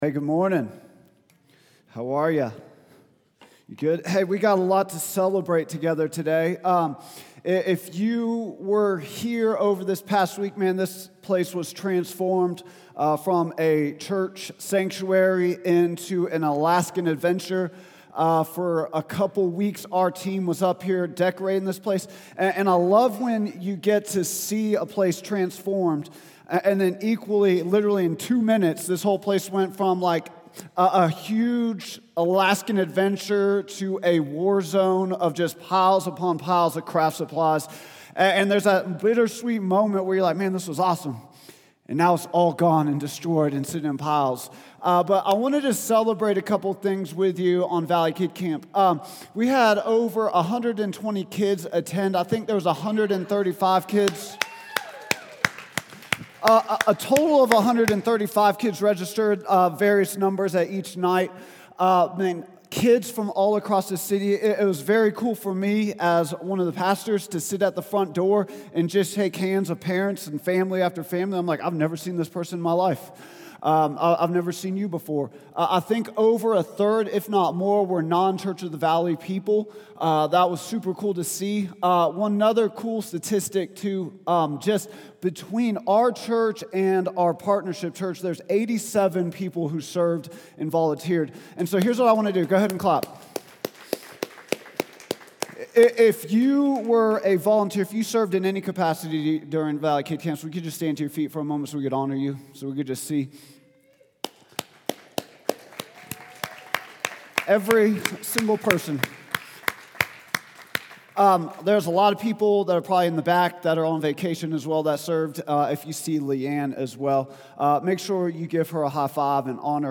Vision & Values Meet Our Team Statement of Faith Sermons Contact Us Give Start of a Movement | Week 9 June 22, 2025